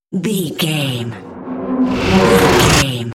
Dramatic riser flashback
Sound Effects
In-crescendo
Thriller
Atonal
tension
ominous
suspense
eerie